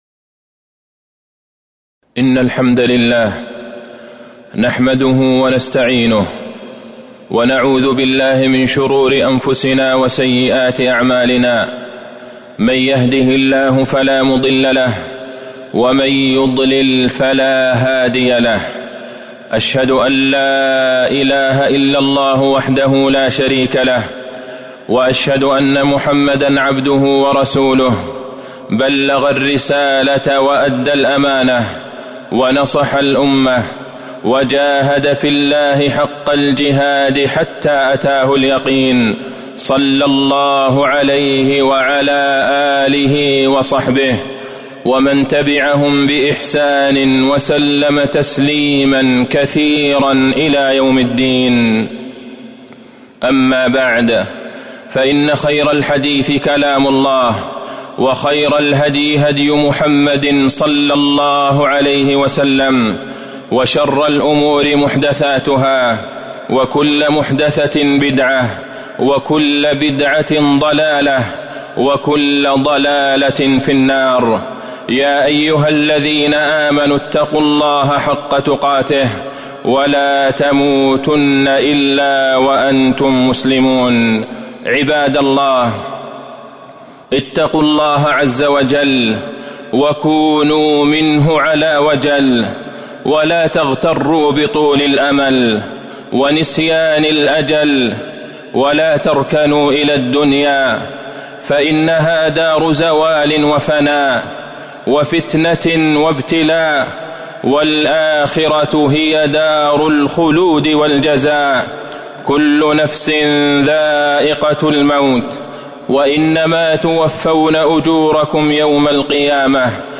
موعظة حر الصيف - الموقع الرسمي لرئاسة الشؤون الدينية بالمسجد النبوي والمسجد الحرام
تاريخ النشر ١٥ ذو القعدة ١٤٤٢ هـ المكان: المسجد النبوي الشيخ: فضيلة الشيخ د. عبدالله بن عبدالرحمن البعيجان فضيلة الشيخ د. عبدالله بن عبدالرحمن البعيجان موعظة حر الصيف